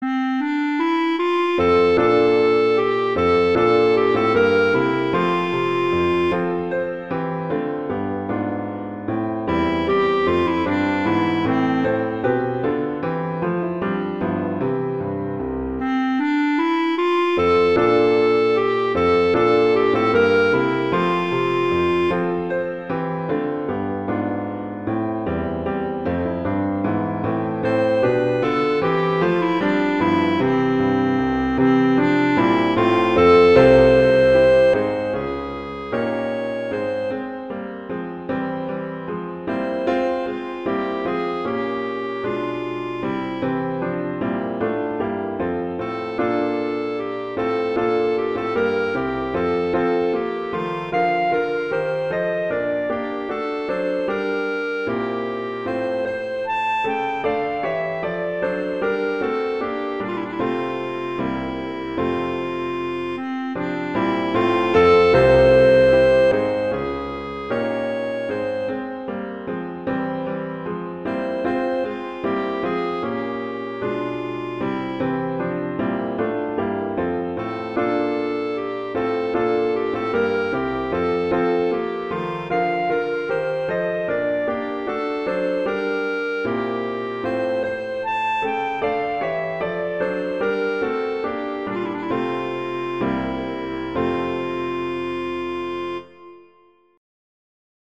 arrangement for clarinet and piano
classical, holiday, winter
F major
♩=76 BPM